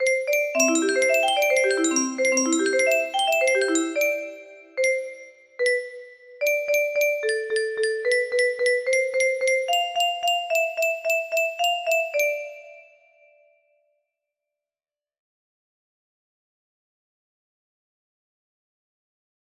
x1 music box melody